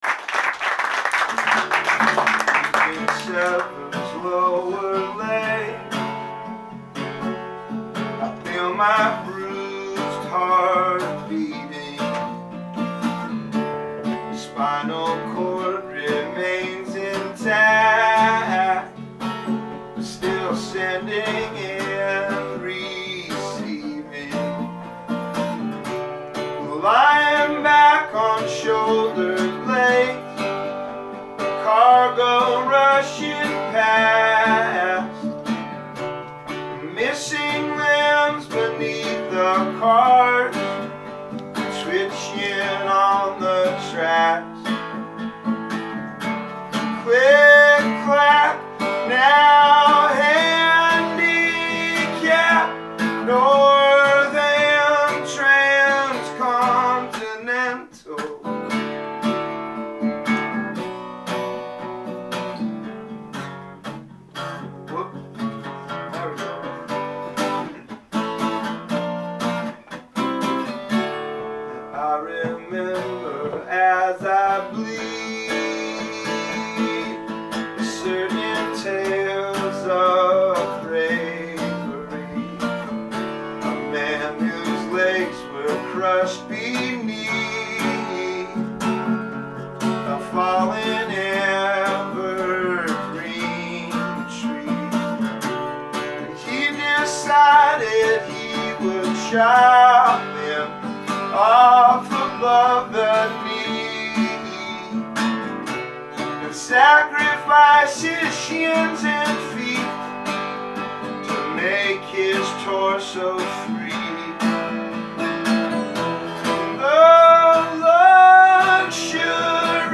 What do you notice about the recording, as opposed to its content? An intimate house performance